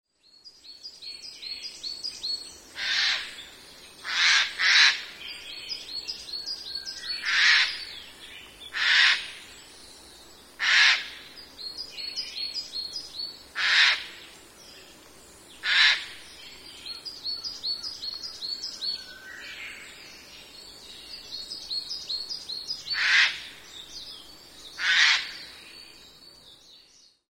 kakesu_c1.mp3